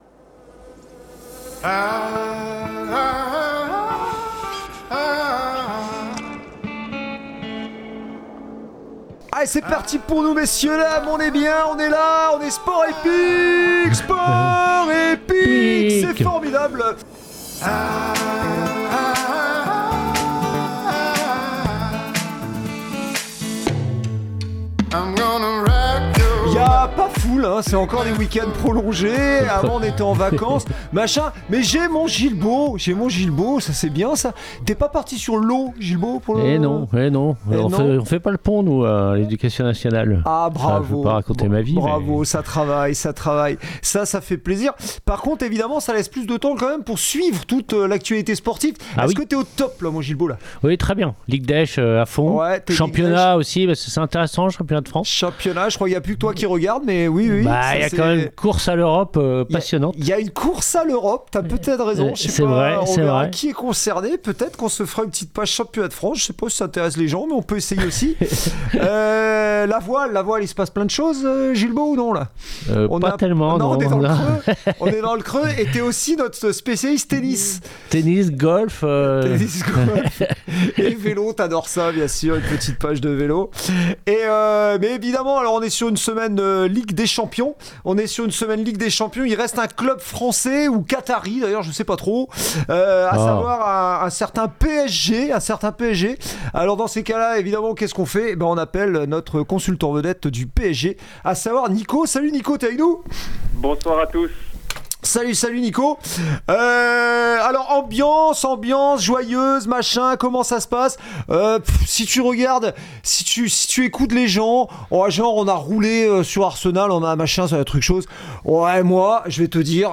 Interviews, quizz, chroniques, débats et bonne humeur seront au rendez-vous!